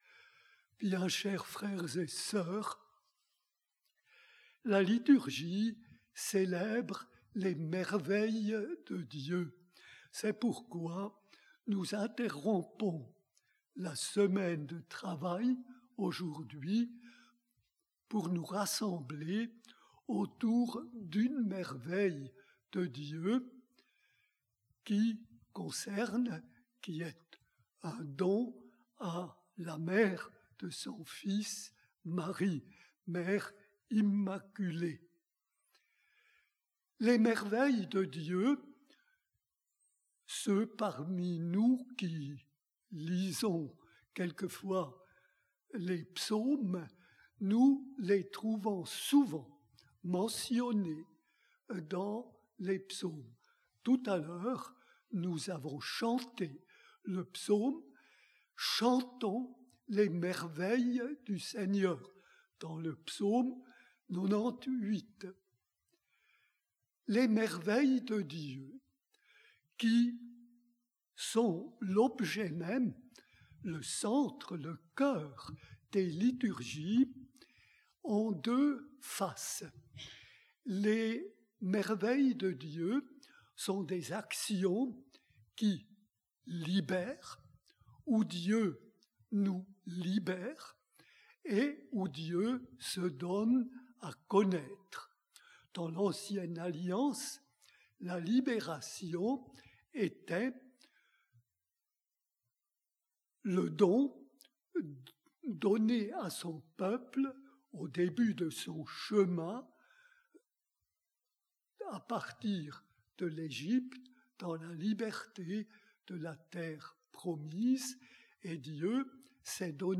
Pour découvrir son homllie, nous vous invitons à écouter un enregistrement en direct.